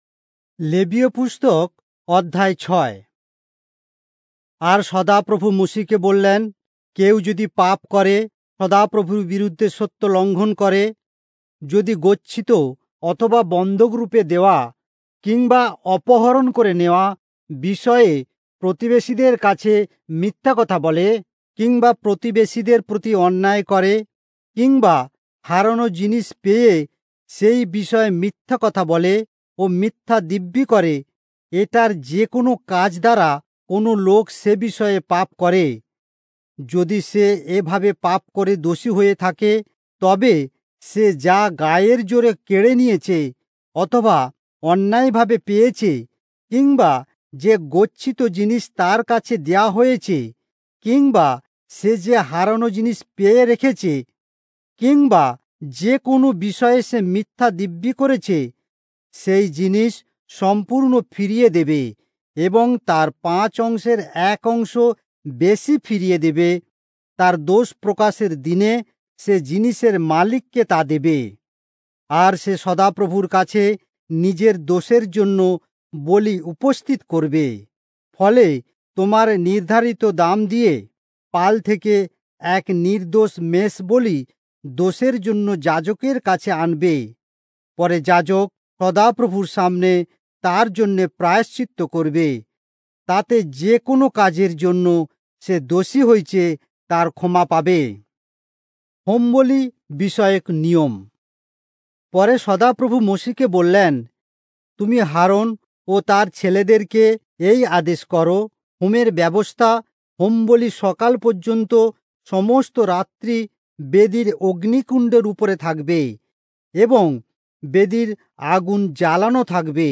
Bengali Audio Bible - Leviticus 2 in Irvbn bible version